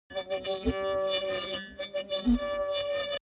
Gemafreie Sounds: Metall